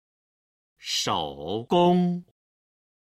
今日の振り返り！中国語発声
01-shougong.mp3